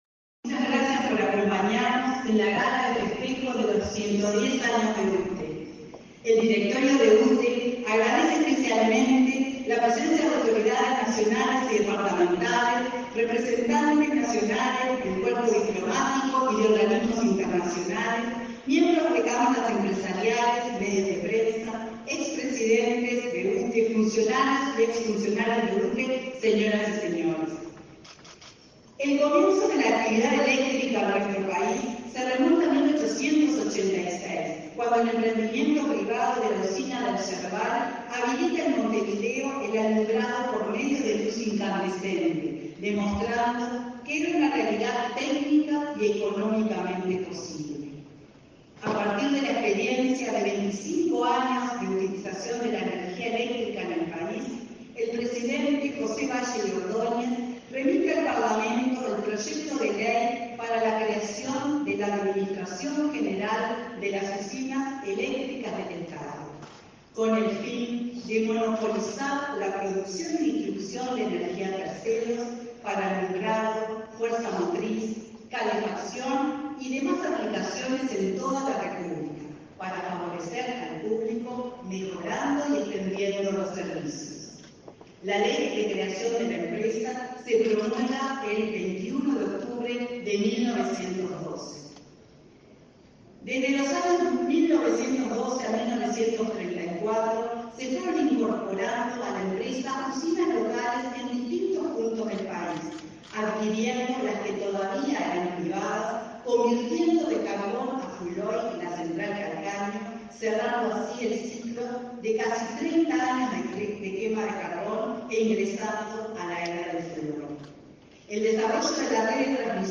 Conferencia de prensa por el 110.° aniversario de UTE
Conferencia de prensa por el 110.° aniversario de UTE 13/10/2022 Compartir Facebook X Copiar enlace WhatsApp LinkedIn Este 12 de octubre, la UTE celebró los 110 años de su fundación. Participaron en el acto el ministro de Industria, Energía y Minería, Omar Paganini, y la presidenta de la UTE, Silvia Emaldi.